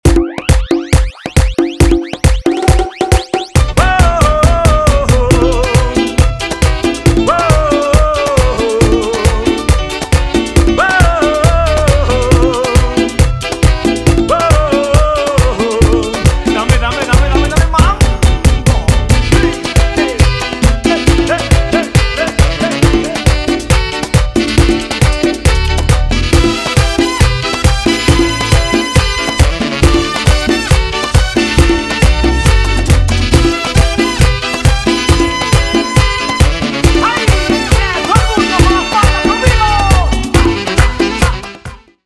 remixes latinos
DJ